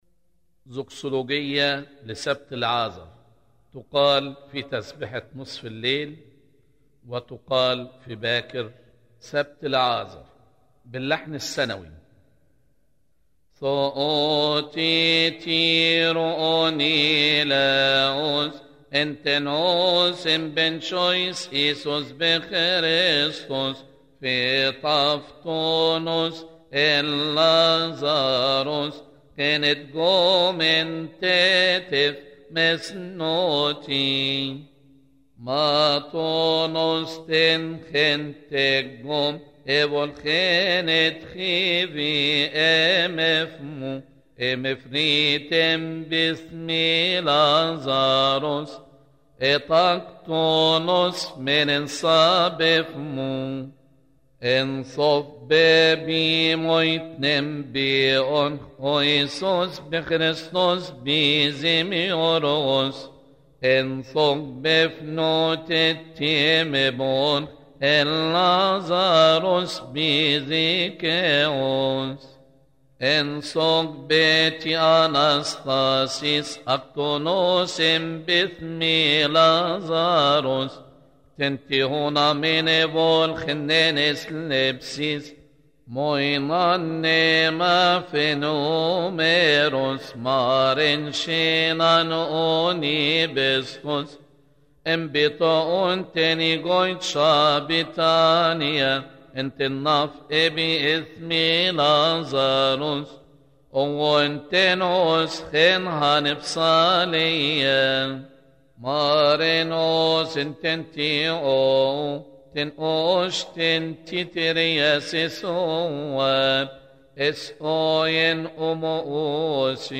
لحن: الذكصولوجية الأولى لسبت لعازر